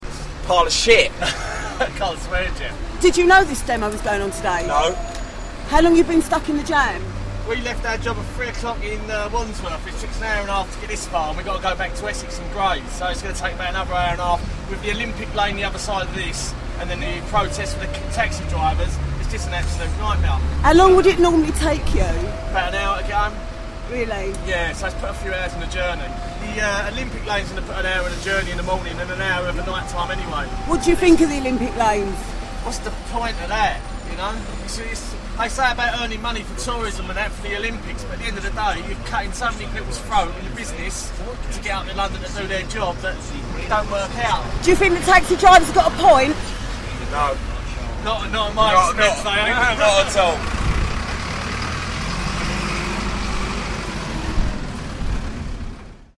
Taxi Dispute Voices 1